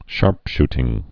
(shärpshtĭng)